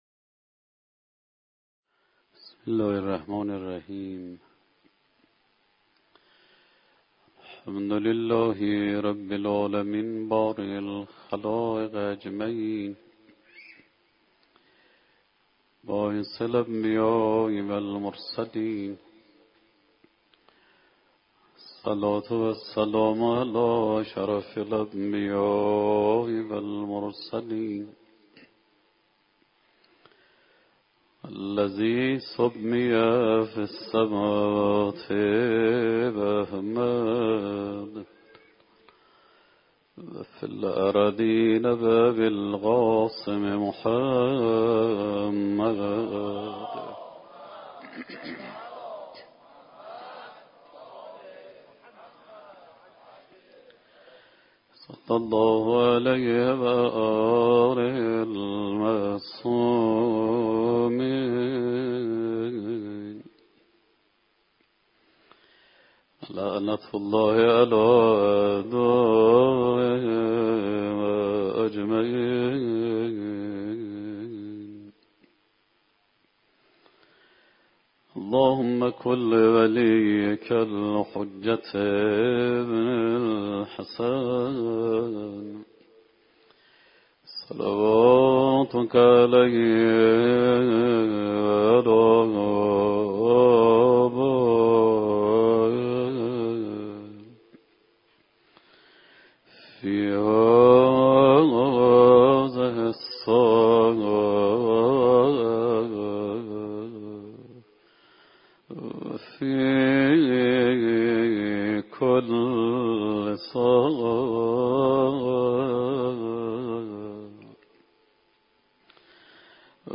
شب اول محرم 97 - بیت الرقیه - سخنرانی